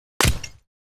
Armor Break 1.wav